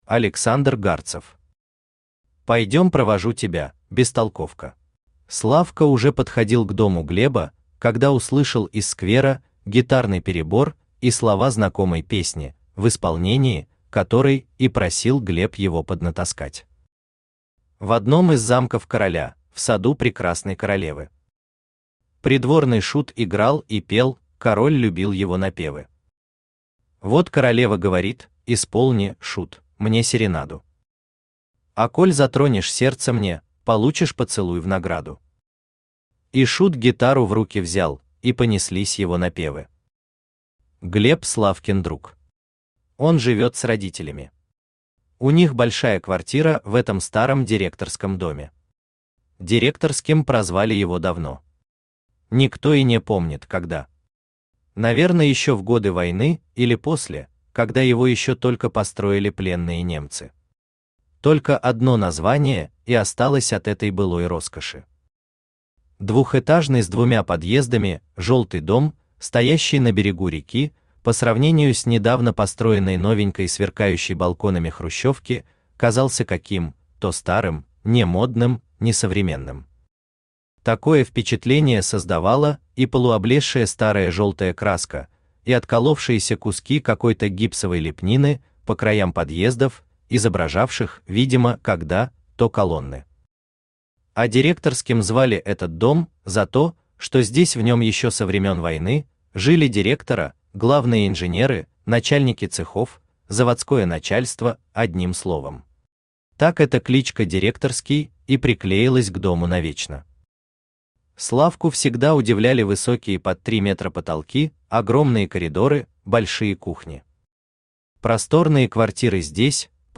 Аудиокнига Пойдем провожу тебя, бестолковка | Библиотека аудиокниг
Aудиокнига Пойдем провожу тебя, бестолковка Автор Александр Гарцев Читает аудиокнигу Авточтец ЛитРес.